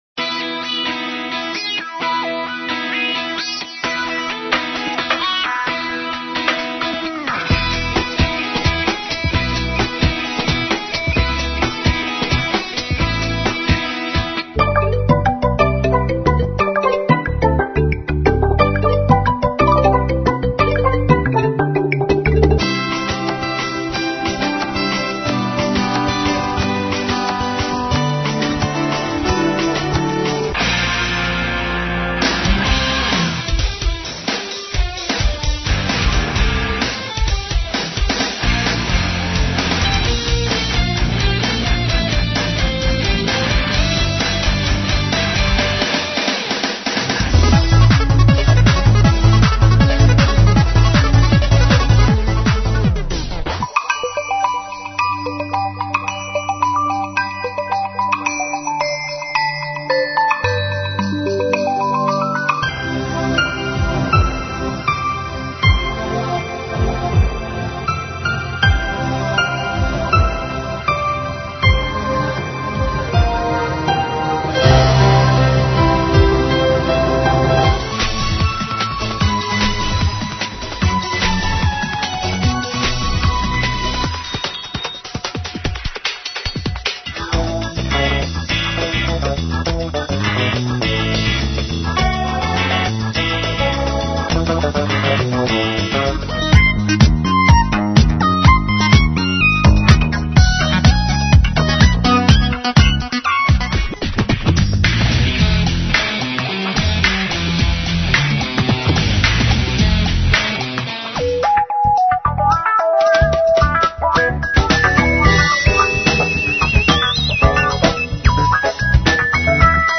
современные инструментальные обработки песен